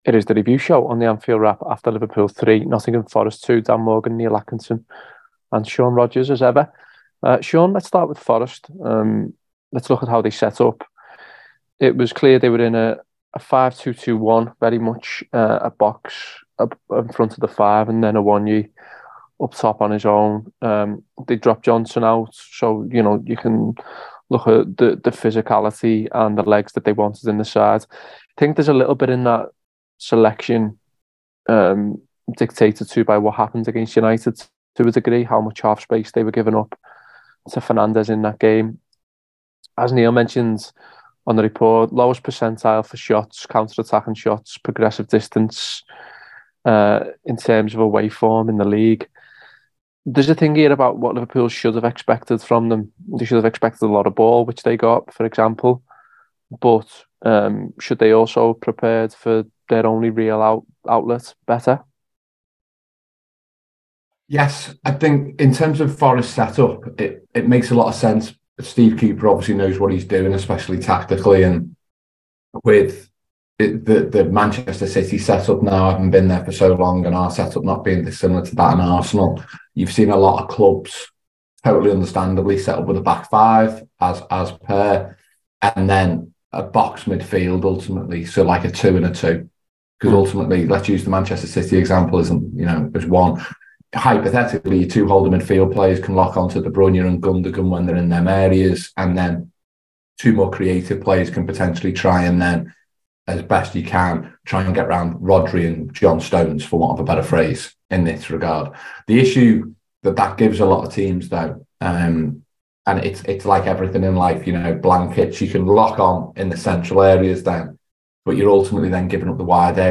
Below is a clip from the show – subscribe for more review chat around Liverpool 3 Nottingham Forest 2…